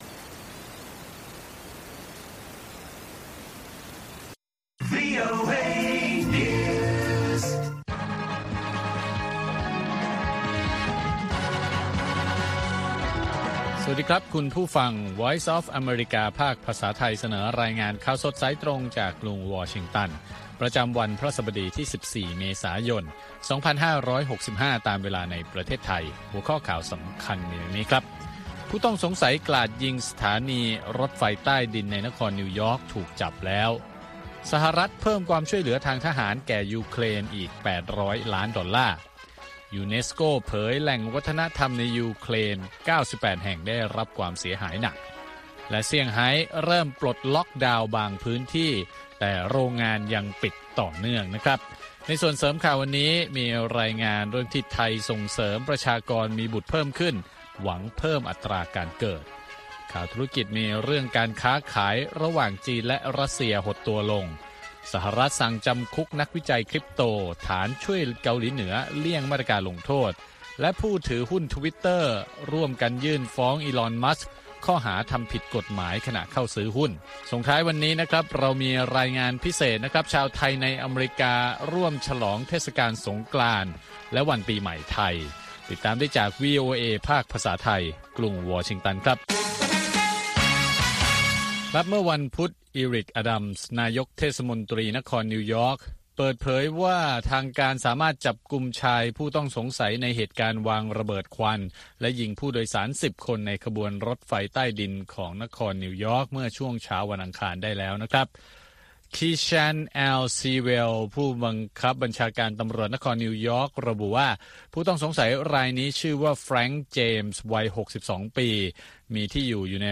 ข่าวสดสายตรงจากวีโอเอ ภาคภาษาไทย 8:30–9:00 น. ประจำวันพฤหัสบดีที่ 14 เมษายน 2565 ตามเวลาในประเทศไทย